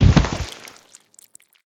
pelt.ogg